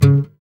Index of /90_sSampleCDs/Sound & Vision - Gigapack I CD 2 (Roland)/GUI_ACOUST. 32MB/GUI_Acoust. Slap